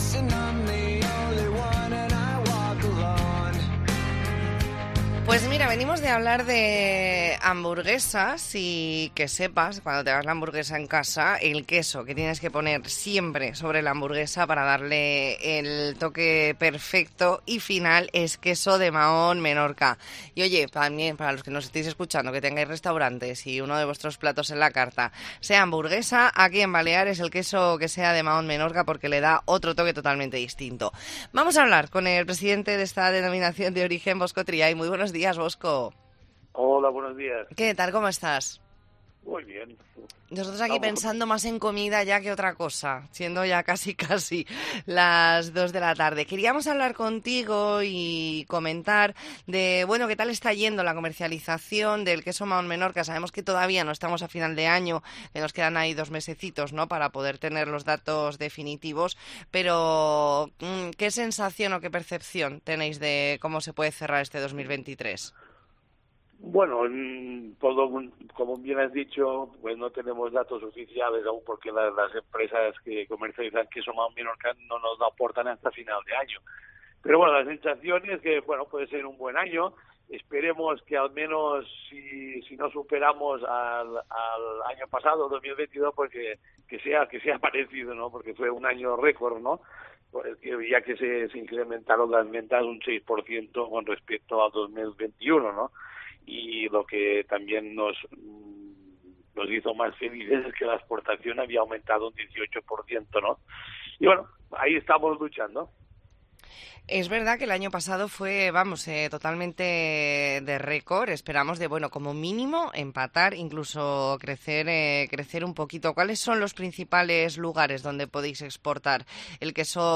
Entrevista en La Mañana en COPE Más Mallorca, lunes 23 de octubre de 2023.